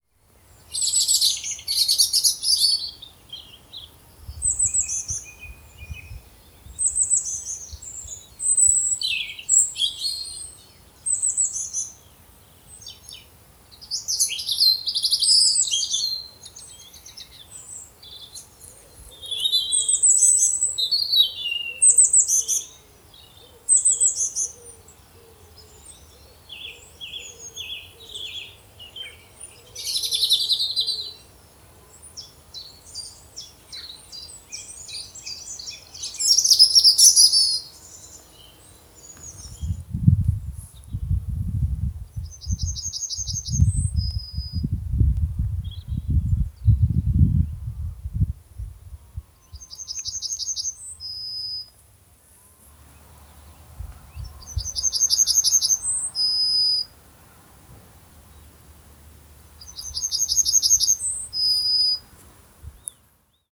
We’re entertained along the way by numerous songbirds – the usual suspects plus, on this occasion, a chiffchaff or two, and last of all a yellowhammer.
Chiffchaff, robin, wren, thrush, wood pigeon, blue tit, yellowhammer…
benthall-birds.ogg